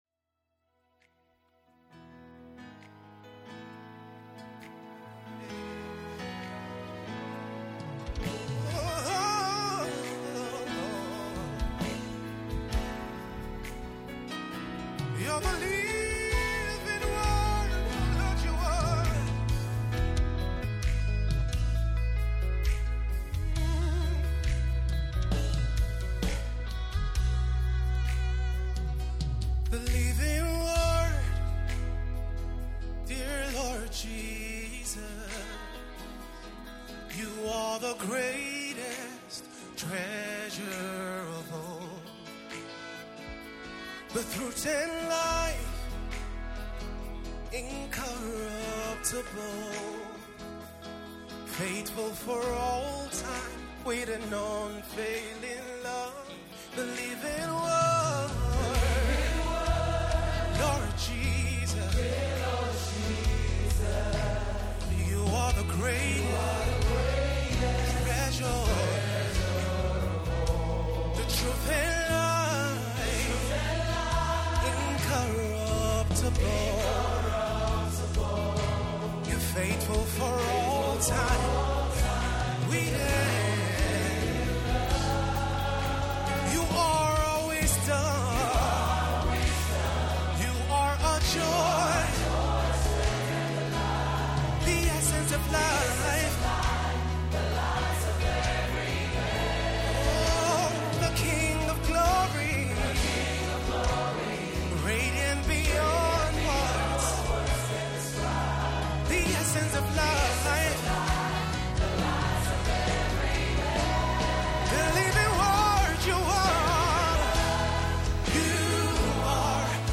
Lyrics, Praise and Worship